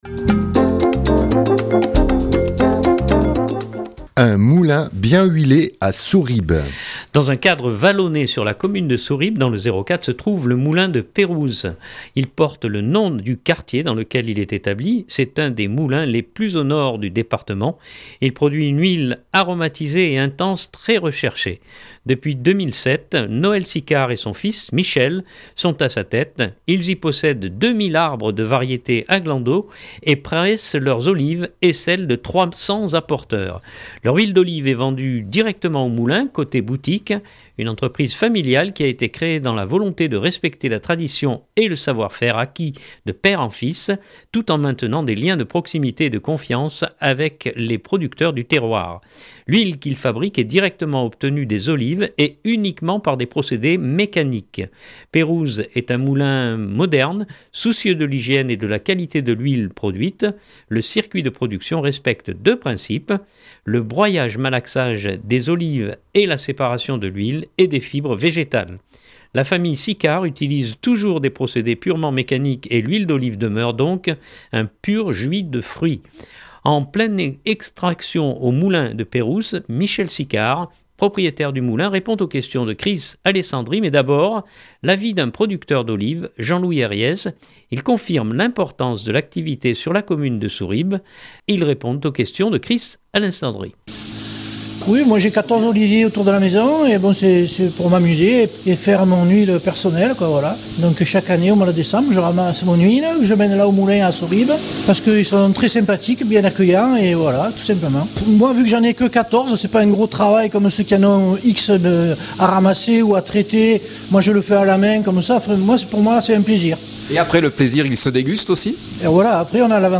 En pleine extraction au Moulin de Peyrouses